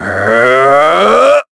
Shakmeh-Vox_Casting4_b.wav